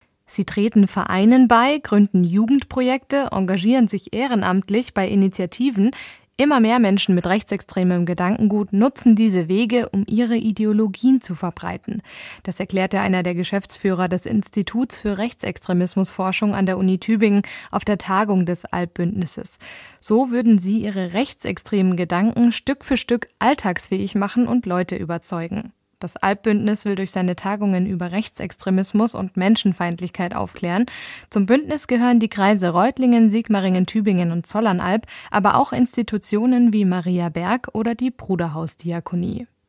Beim Forum des Albbündnisses ging es darum, wie Menschen mit rechtsextremem Gedankengut Vereine und Institutionen unterwandern.